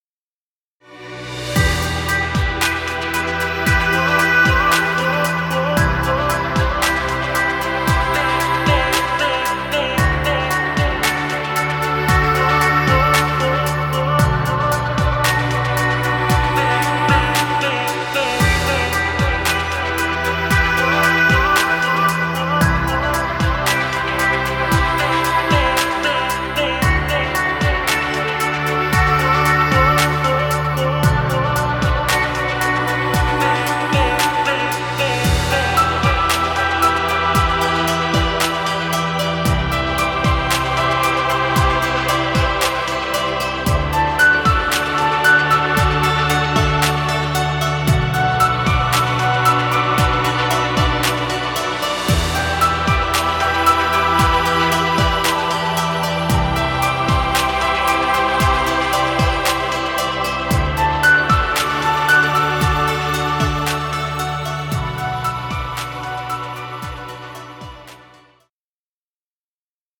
Chillout music. Background music Royalty Free.